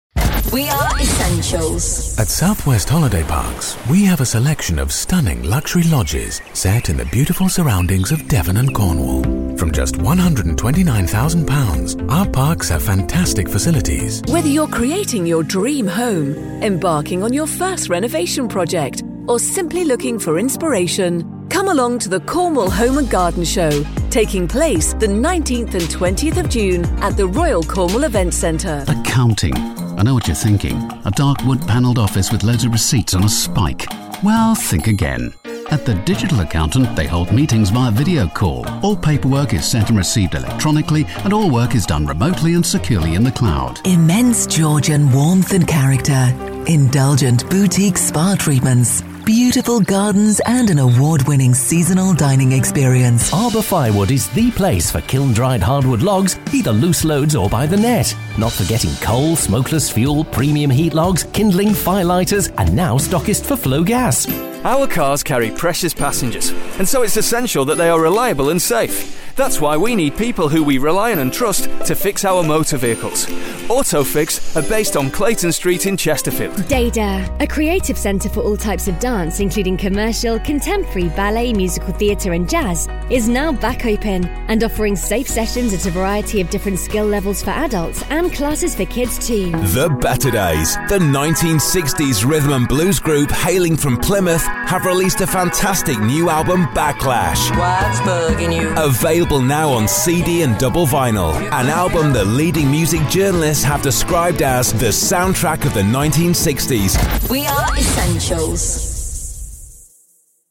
Our first-class scriptwriters and commercial producers create radio commercials that genuinely connect with listeners and draw attention to your brand.
Click The Player Below To Hear A Sample Of Our Script & Production Content: